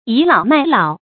yǐ lǎo mài lǎo
倚老卖老发音
成语正音 倚，不能读作“yī”。